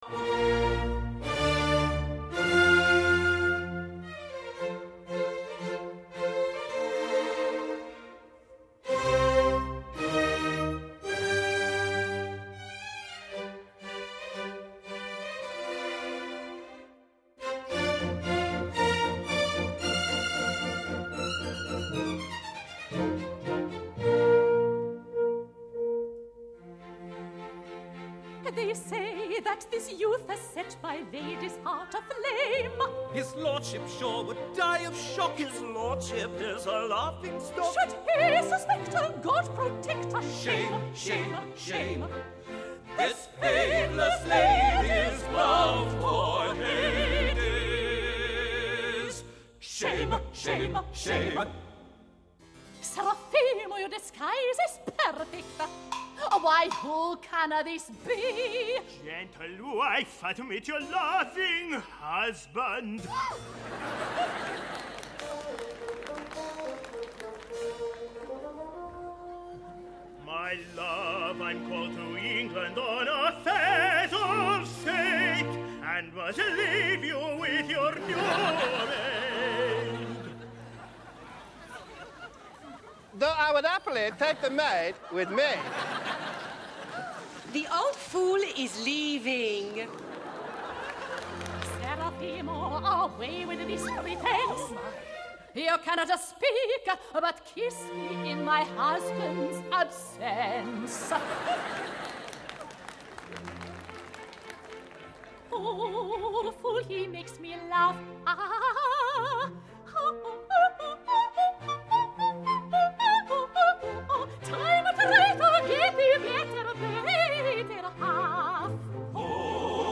音乐类型：电影配乐
集合全好莱坞及百老汇最能歌善舞演员 亲自演唱